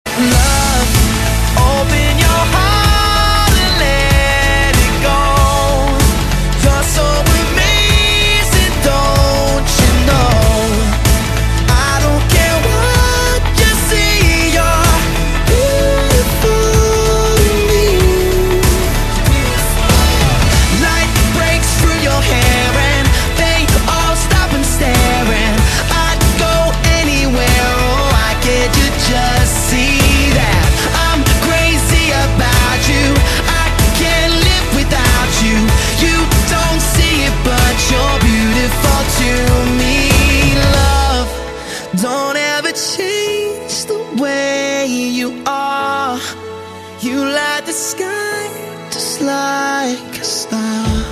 M4R铃声, MP3铃声, 欧美歌曲 39 首发日期：2018-05-15 13:44 星期二